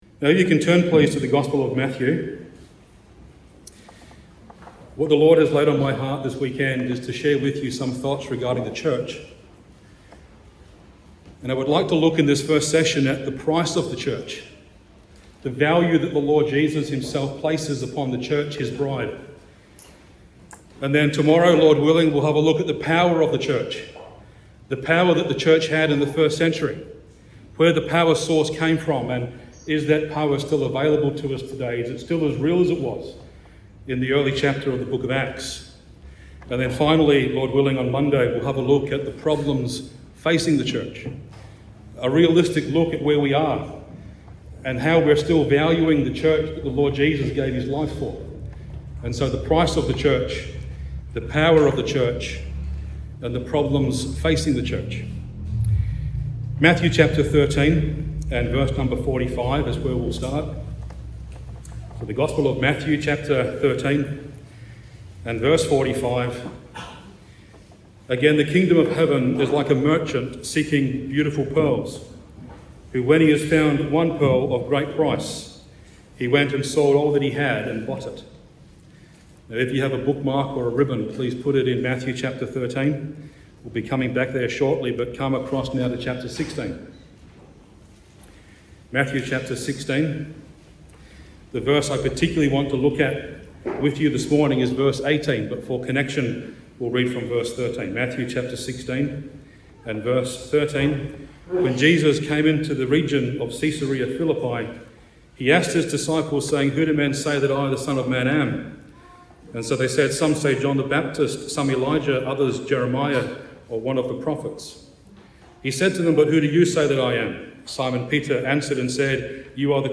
Location: Cooroy Gospel Hall Conference 2025 (Cooroy, QLD, Australia)